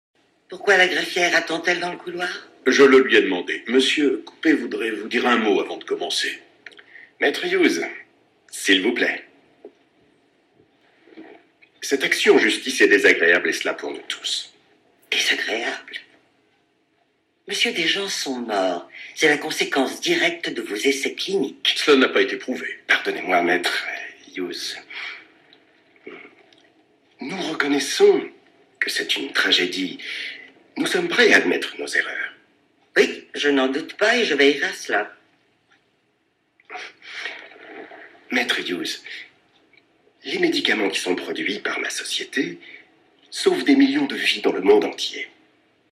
Bandes-son
doublage du personnage "COUPET" dans DAMAGES